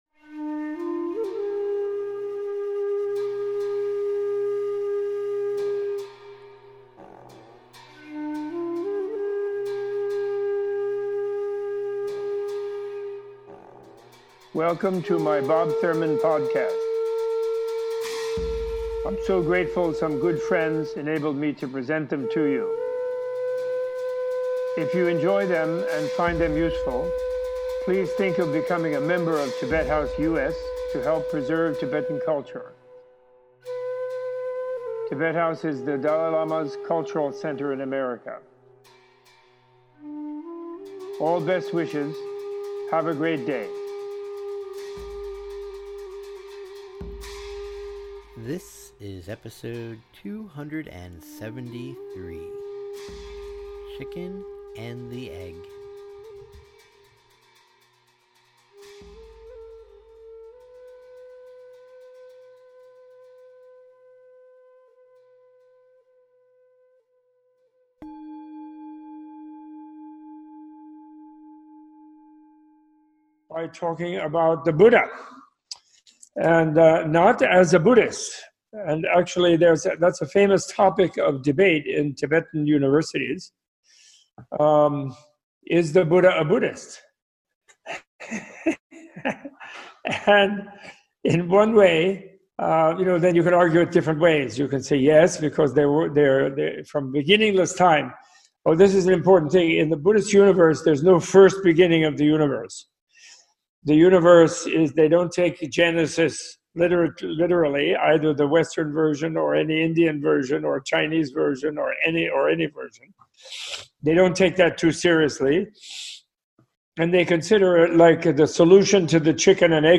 Starting with defining what a Buddha is from a Non-Buddhist perspective, podcast includes a guided meditation on emptiness and an in-depth examination of causality.